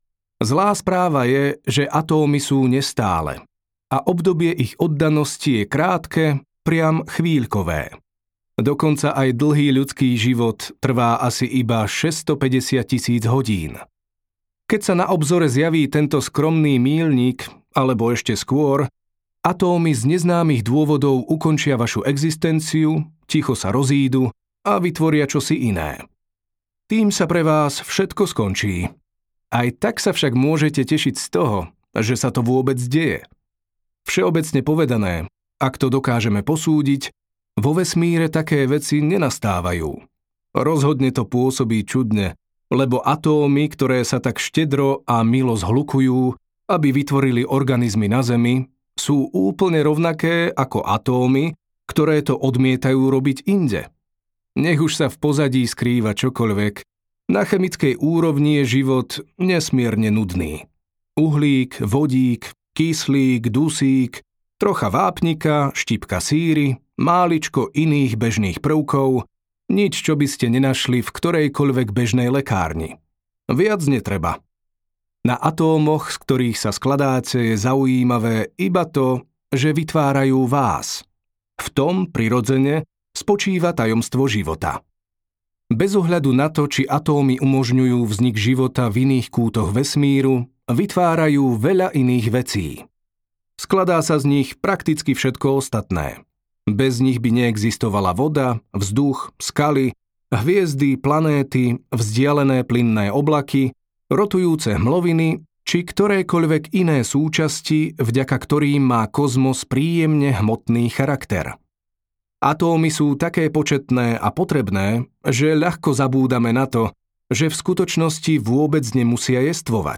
Audiokniha Stručná história takmer všetkého | ProgresGuru